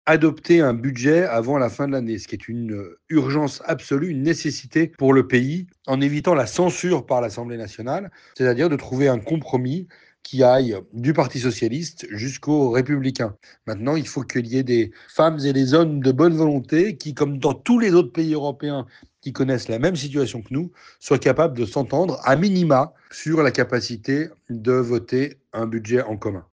Mais de tous les sujets actuellement suspendus la question du budget pour 2026 apparaît comme une priorité évidente pour le sénateur de Marnaz, Loïc Hervé.